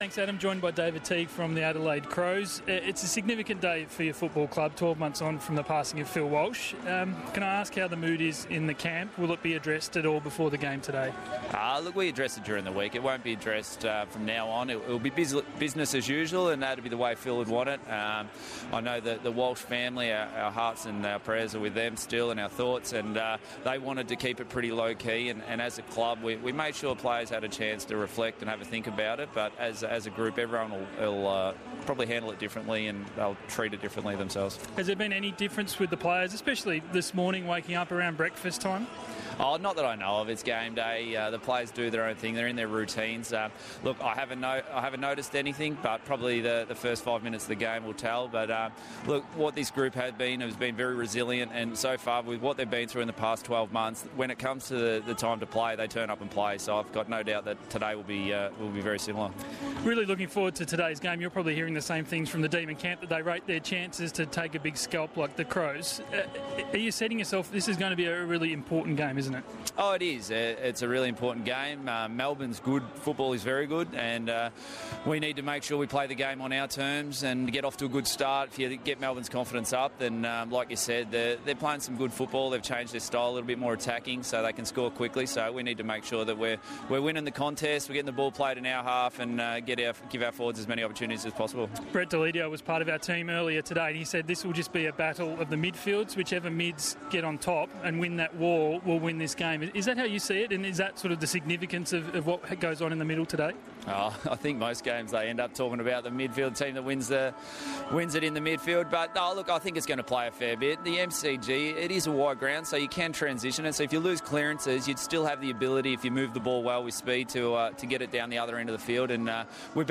David Teague on ABC radio